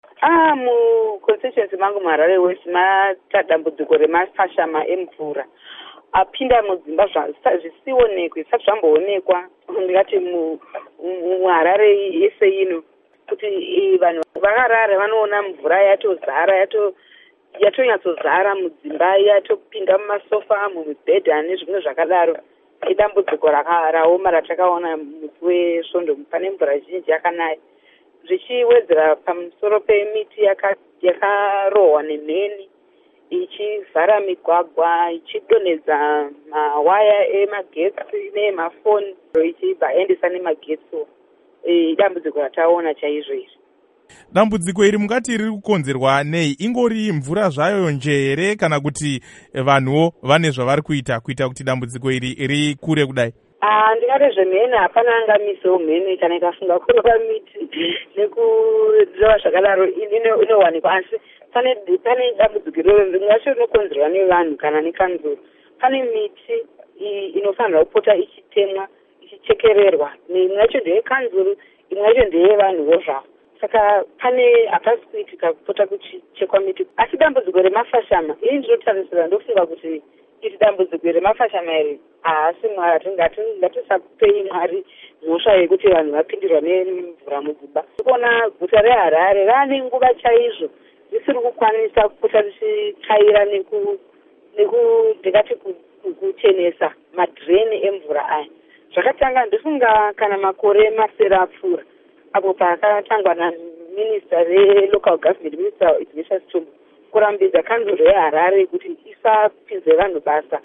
Hurukuro naMuzvare Jessie Majome